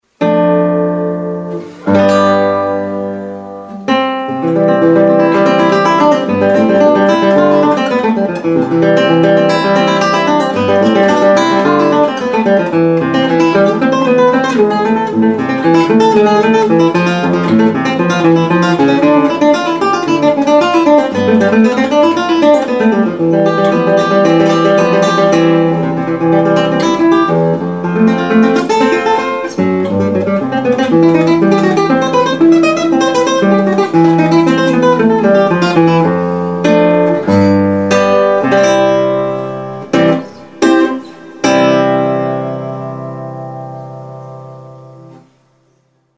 classical
classical.mp3